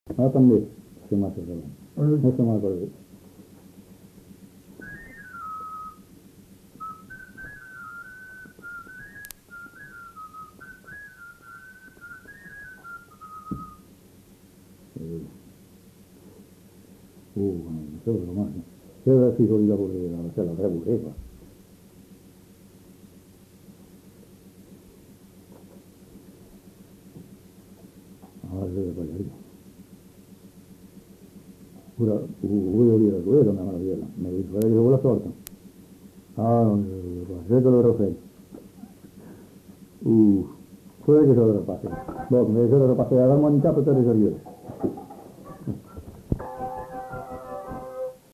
Aire culturelle : Gabardan
Lieu : Estigarde
Genre : morceau instrumental
Instrument de musique : flûte de Pan
Danse : bourrée